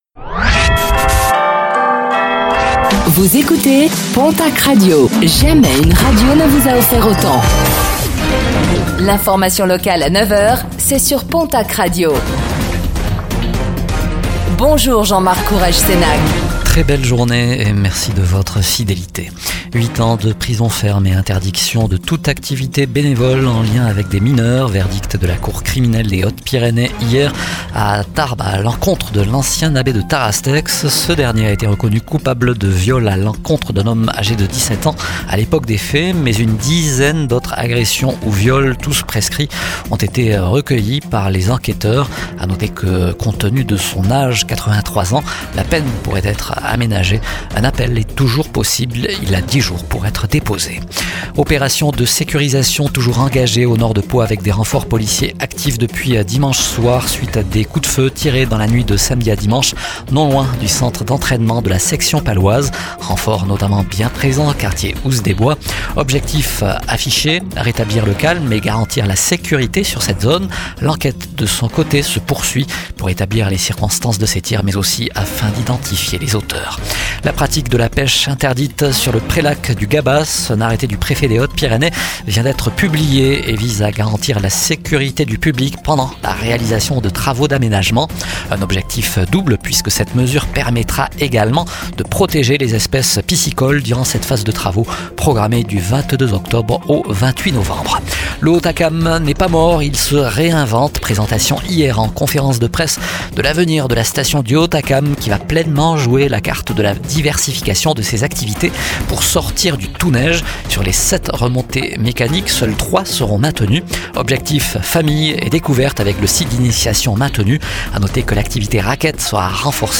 09:05 Écouter le podcast Télécharger le podcast Réécoutez le flash d'information locale de ce mardi 21 octobre 2025